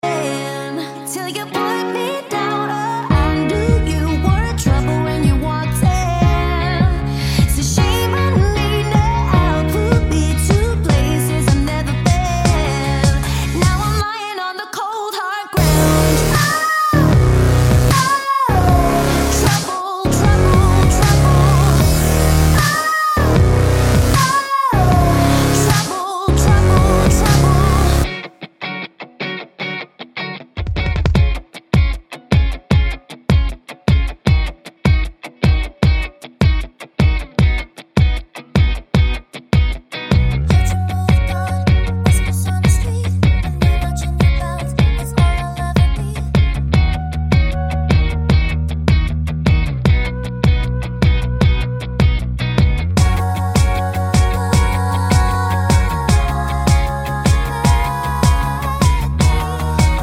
With Lead on V1 and Chorus 1 Pop (2010s) 3:39 Buy £1.50